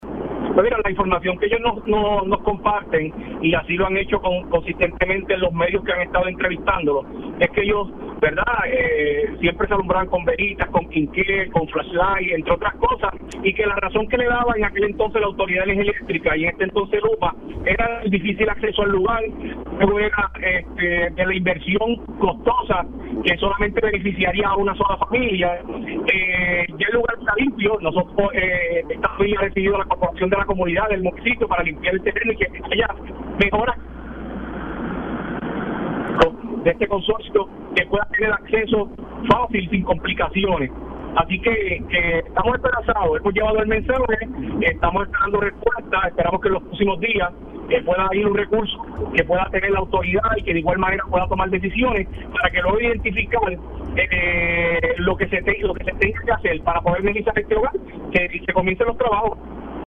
512-JOSEAN-GONZALEZ-ALC-PEnUELAS-PAREJA-NO-HA-TENIDO-LUZ-POR-48-AnOS-POR-LEJANIA-DE-SU-RESIDENCIA.mp3